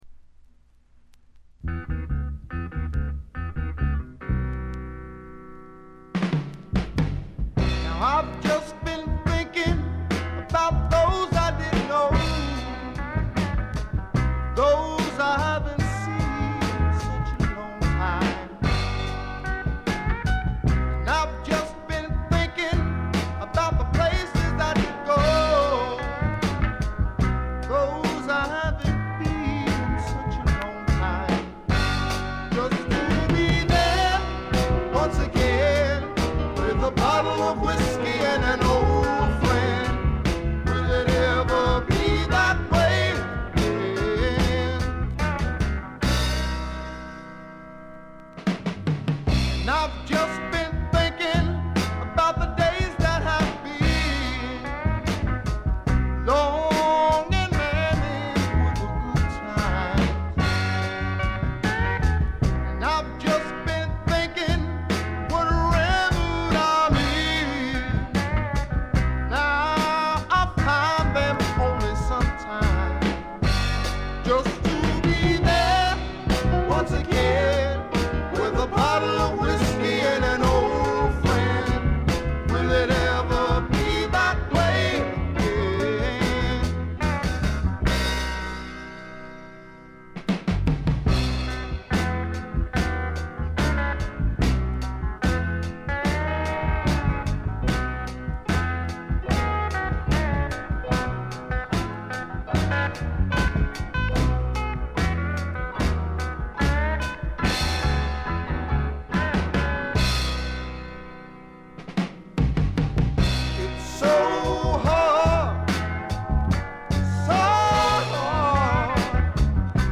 で内容はというとザ・バンドからの影響が色濃いスワンプ裏名盤であります。
試聴曲は現品からの取り込み音源です。